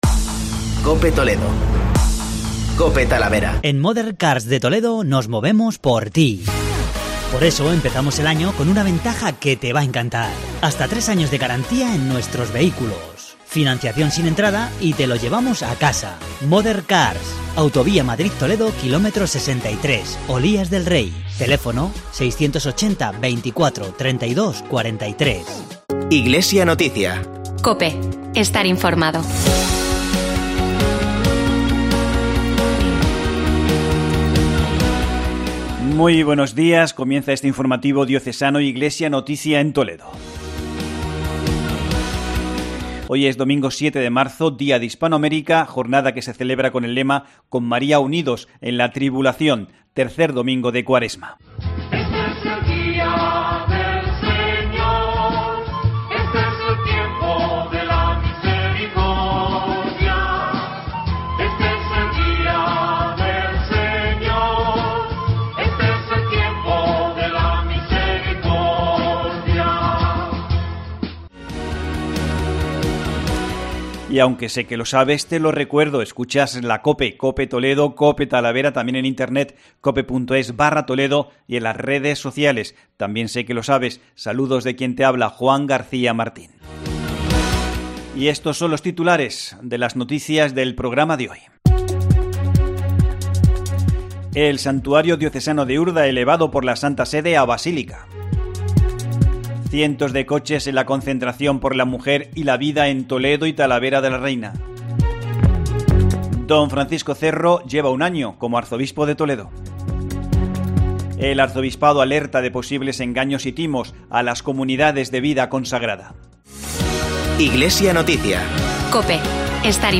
Informativo Diocesano Iglesia en Toledo